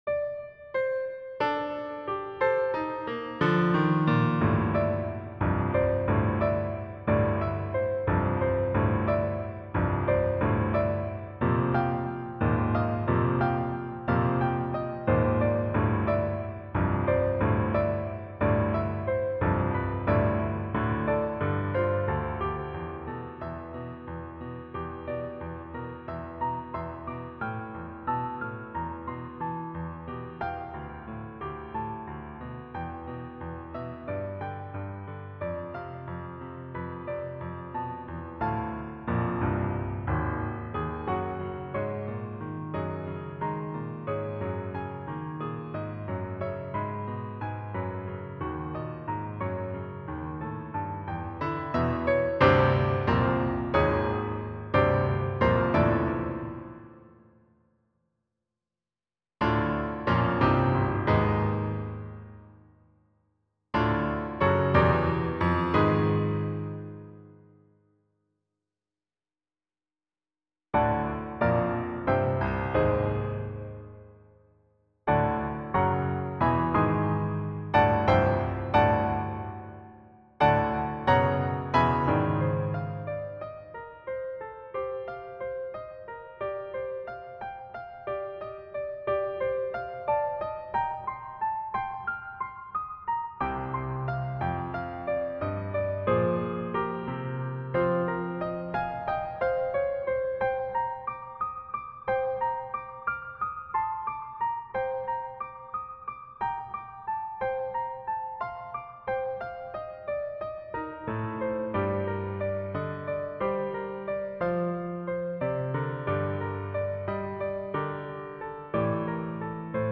Complete, a tempo, no click
Trumpet, violin, and piano